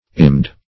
Imbed \Im*bed"\, v. t. [imp.